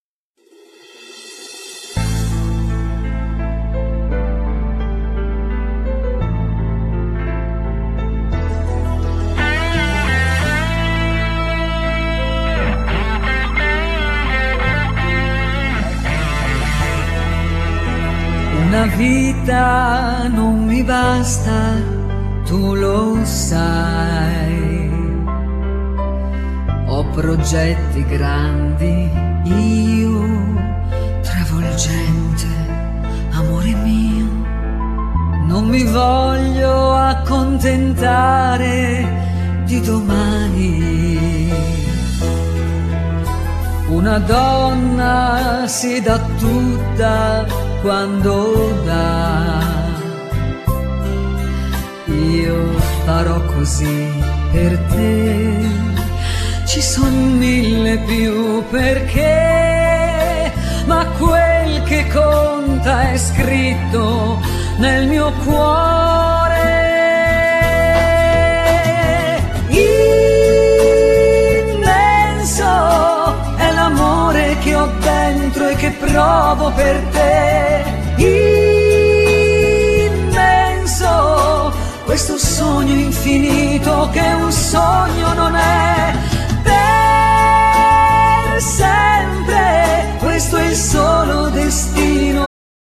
Genere : Folk